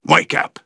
synthetic-wakewords
ovos-tts-plugin-deepponies_Sniper_en.wav